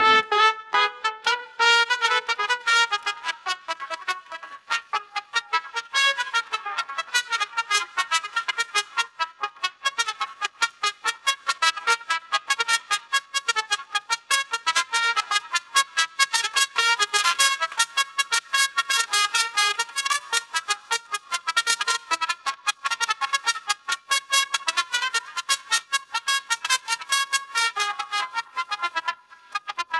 Facebook's musicgen is a model that generates snippets of audio from a text description - it's effectively a Stable Diffusion for music.
trumpet_mariachi.wav